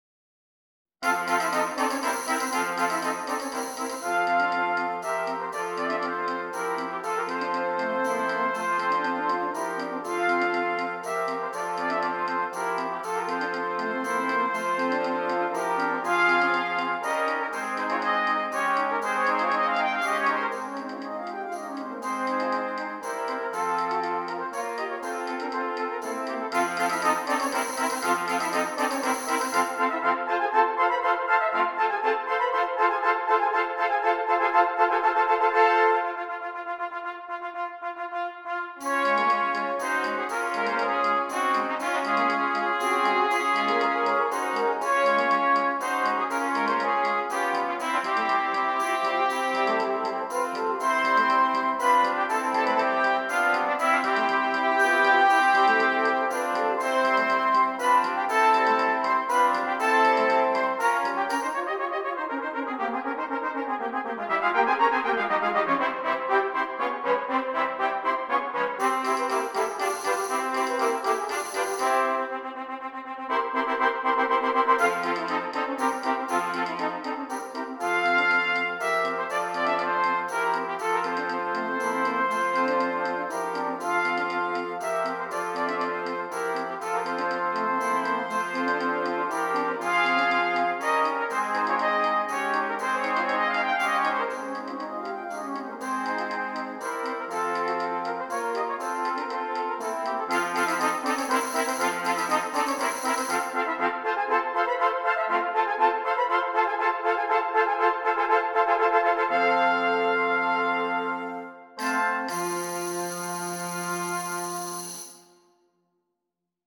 7 Trumpets